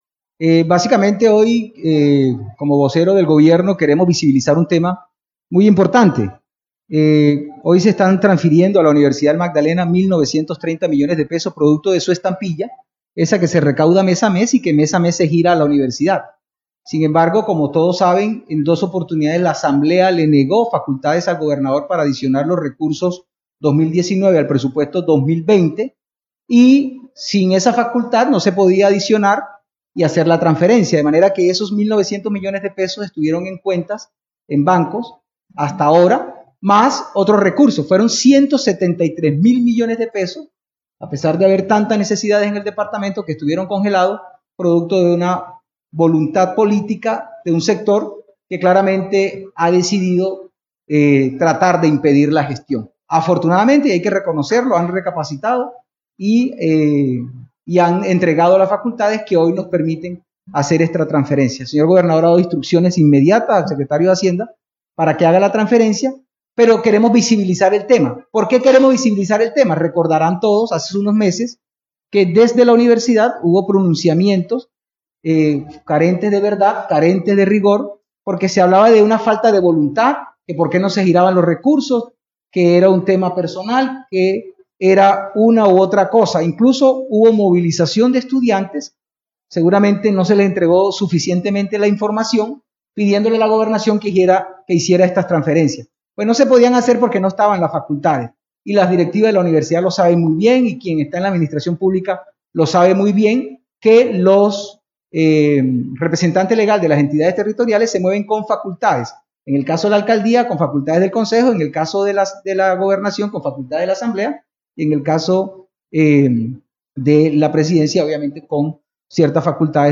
El mismo Martínez así lo confirmó en unas declaraciones públicas, en las que dijo que (toca las siguientes comillas para escuchar el audio):
Audio de Rafael Martínez durante la entrega del cheque de papel.
vocero_del_gabinete.mp3